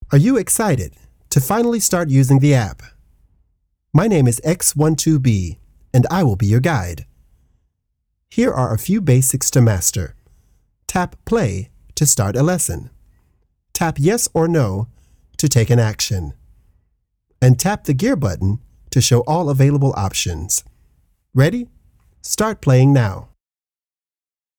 Professional voice actor, vocalist, and recording artist with a warm, grounded delivery and strong long-form consistency.
Voice Sample- Animation/Video game
General American, Neutral US
I am a full-time voice actor and professional vocalist working from a broadcast-quality home studio.
Voice Sample- Animation:Video game.mp3